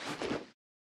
equip_generic6.ogg